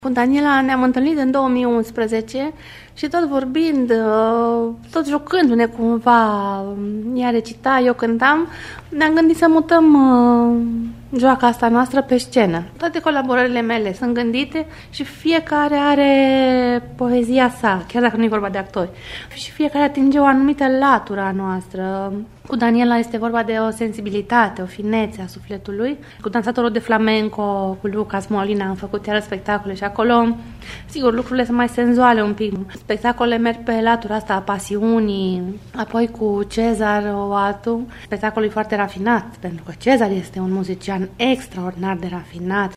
spectacole de poezie şi muzică